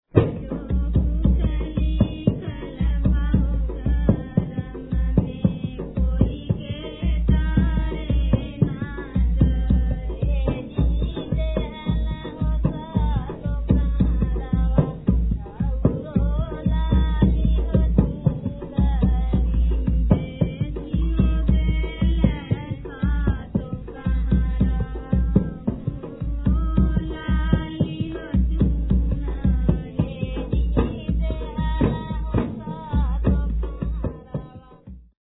Vocal Folk - Bhajan